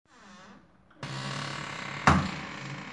弗利的做法 " Puerta cerrandose
描述：门关闭丝毫吱吱声
Tag: 咯吱 以利 关闭